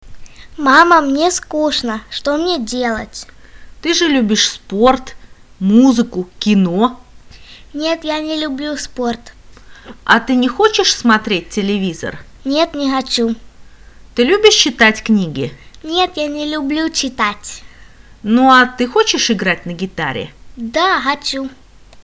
Kuuntele monta kertaa keskustelu klikkaamalla